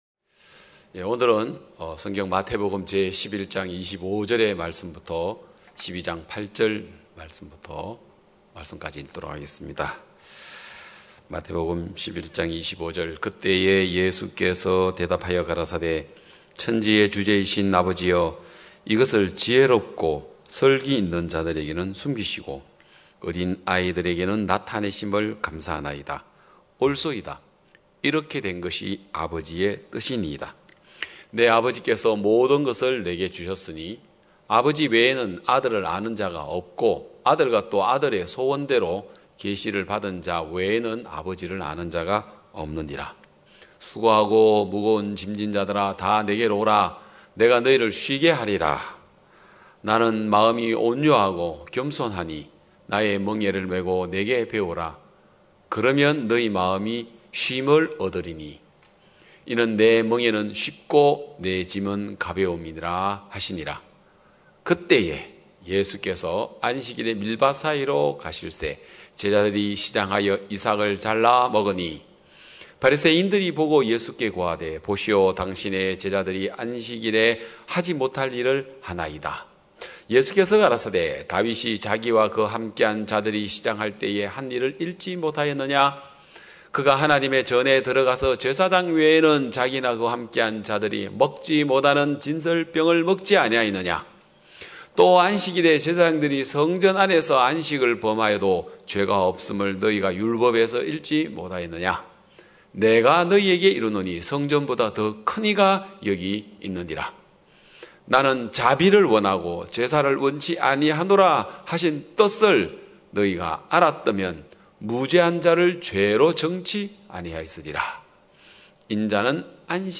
2021년 7월 25일 기쁜소식양천교회 주일오전예배